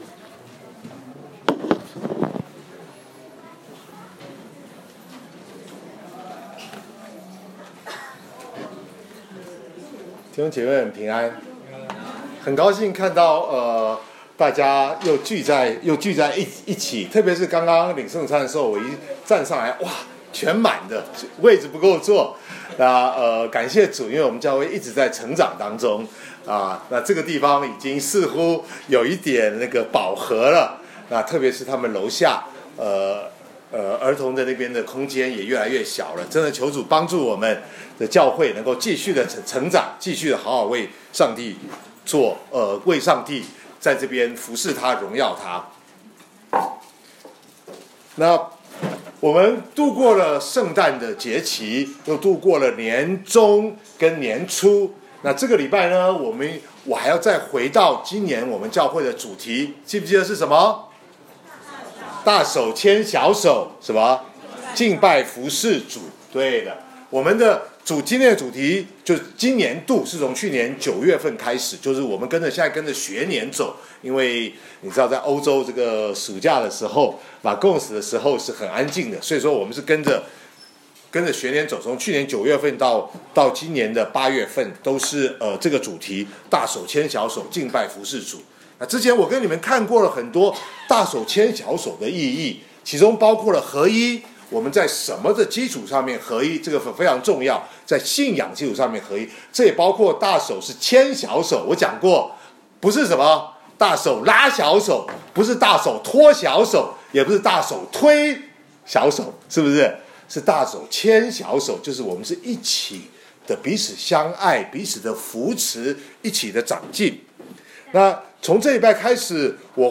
2019年1月13日主日讲道